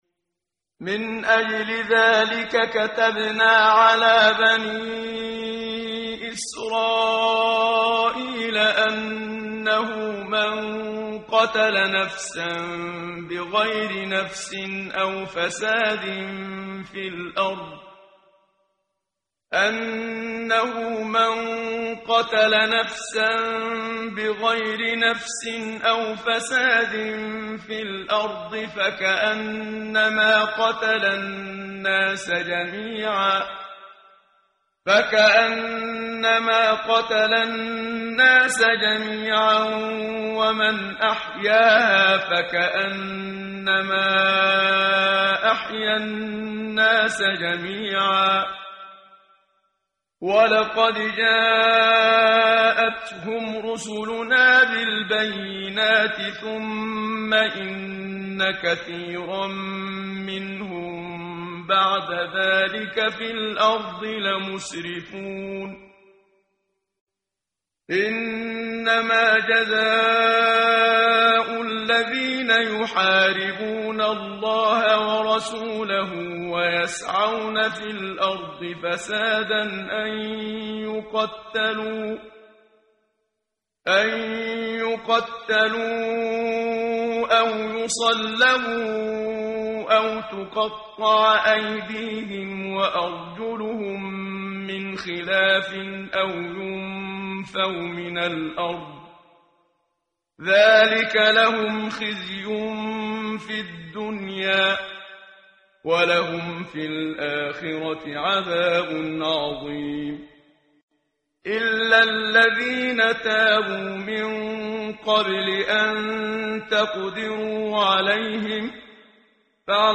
ترتیل صفحه 113 سوره مبارکه المائده (جزء ششم) از سری مجموعه صفحه ای از نور با صدای استاد محمد صدیق منشاوی